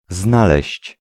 Ääntäminen
IPA: [vɪn.dǝː] Tuntematon aksentti: IPA: /ˈvɪn.də(n)/